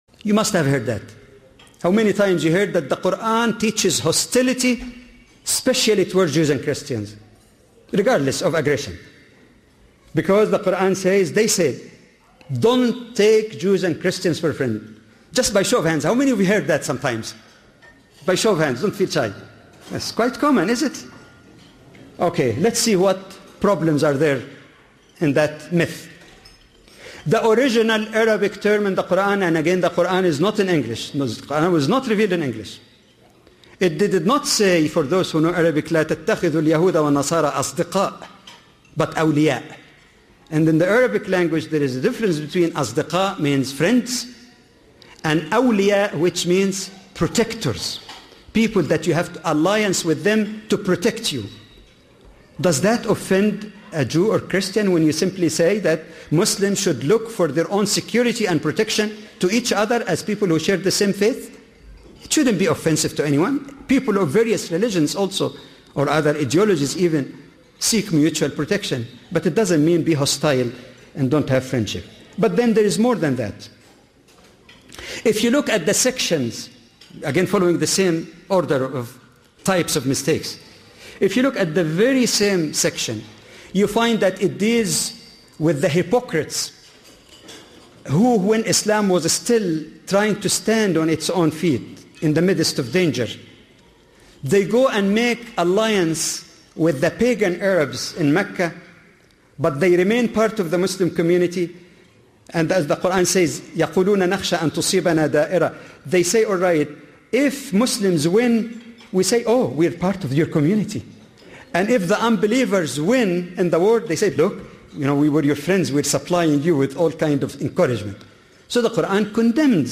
A lecture